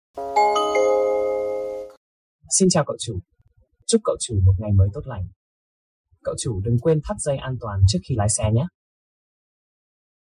Âm thanh Chào khởi động Xe Ô tô Tiếng Việt Âm Thanh Chào Carplay
Thể loại: Tiếng chuông, còi
am-thanh-xin-chao-cau-chu-carplay-www_tiengdong_com.mp3